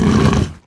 spawners_mobs_uruk_hai_death.ogg